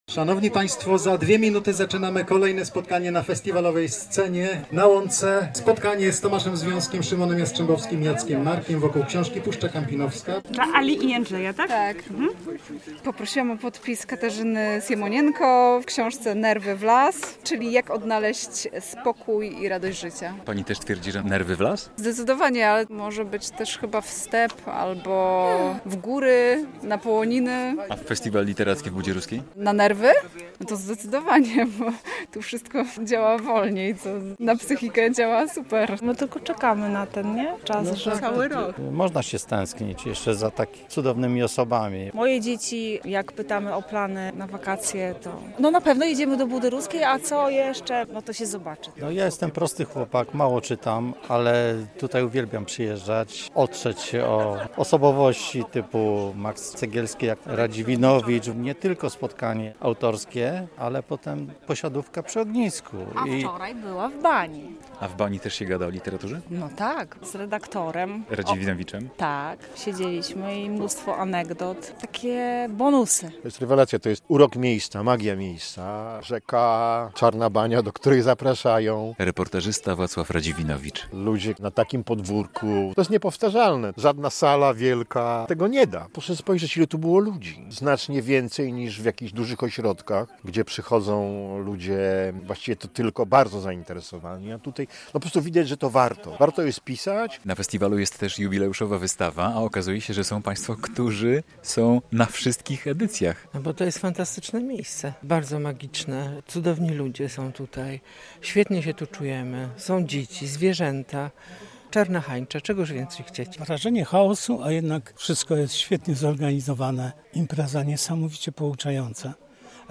W Budzie Ruskiej zakończyła się 10. edycja festiwalu literackiego "Patrząc na Wschód" - relacja